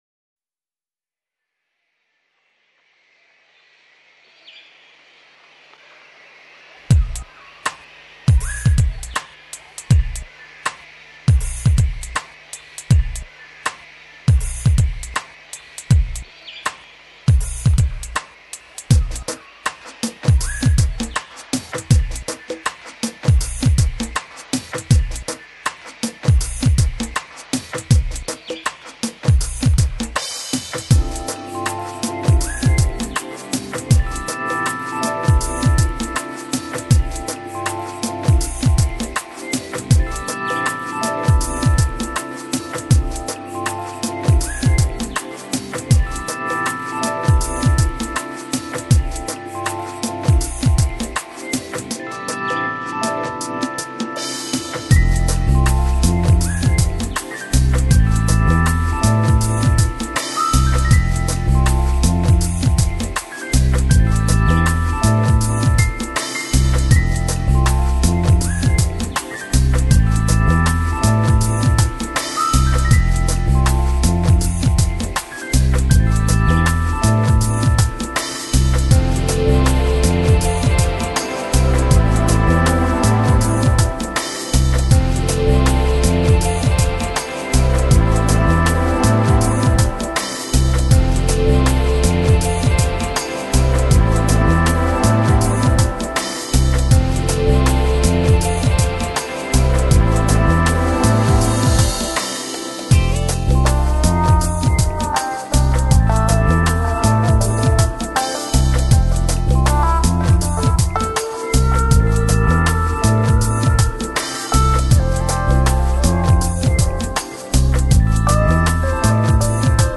Жанр: Lounge Future Jazz Downtempo